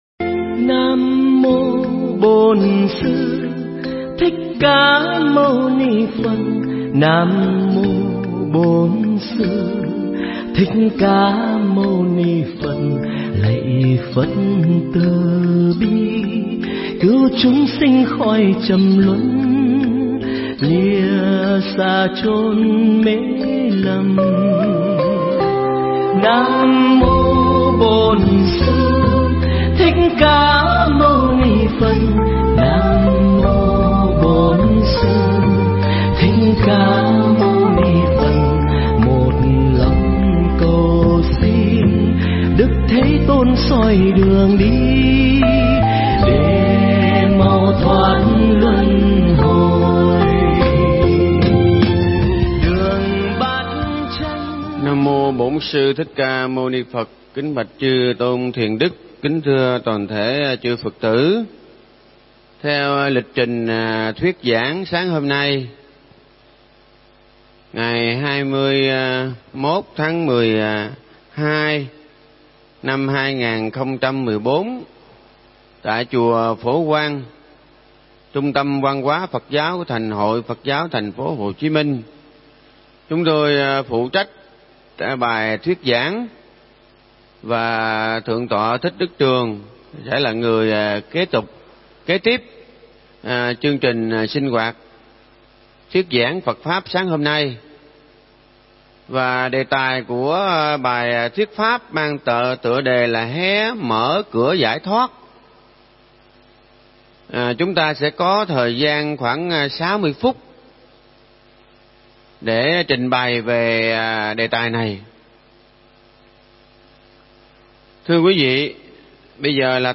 Nghe Mp3 thuyết pháp Hé Mở Cửa Giải Thoát